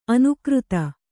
♪ anukřta